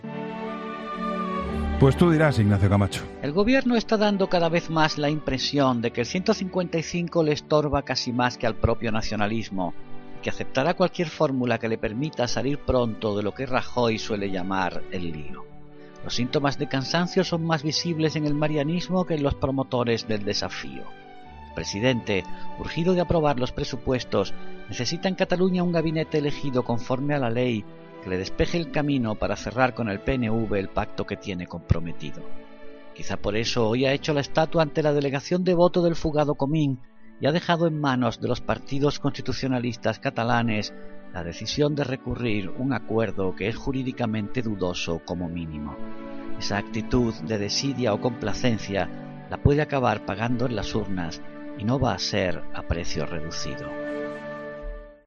Ignacio Camacho habla en 'La Linterna' de cómo ha respondido el Gobierno de Rajoy a la delegación de voto del fugado Comín